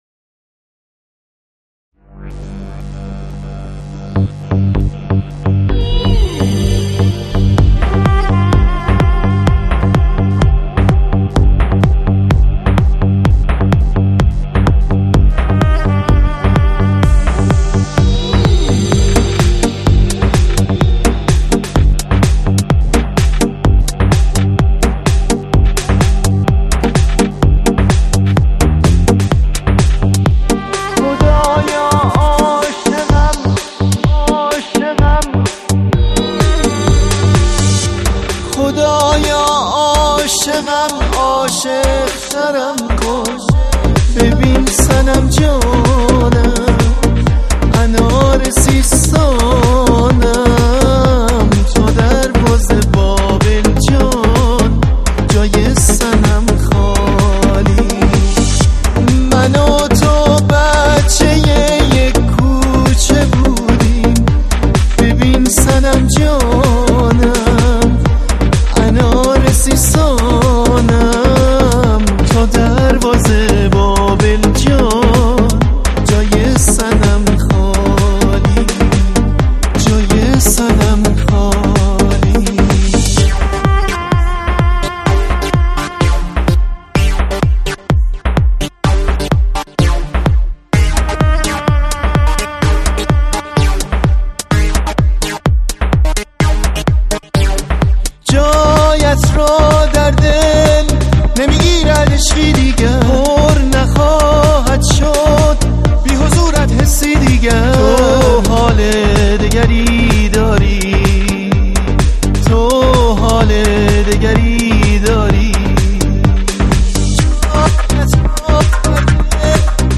بلوچی زابلی سیستانی